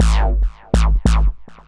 BL 142-BPM G#.wav